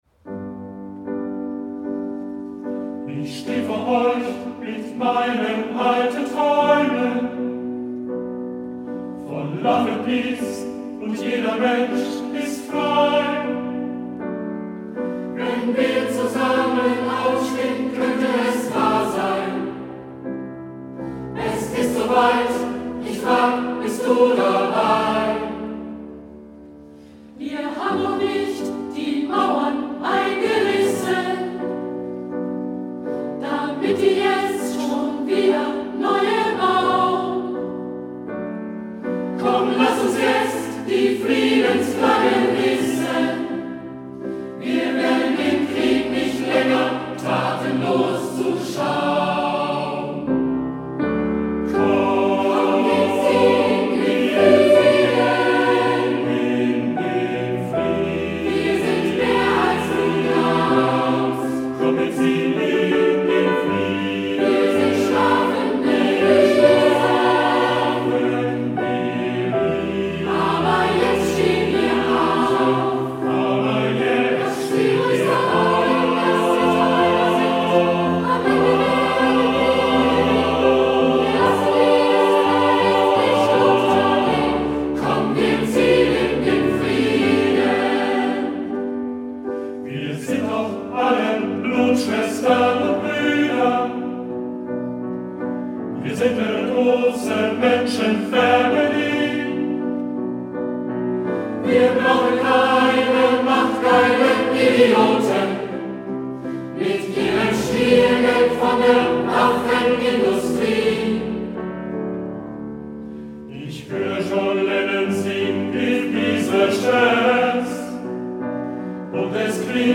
In Hitzacker hat sich das 'Ensemble von Menschen für den Frieden' gefunden: mit einem eingeübten Chorbeitrag beteiligten sich die Sängerinnen und Sänger an dem großen Chor-Experiment des NDR
Chor-Experiment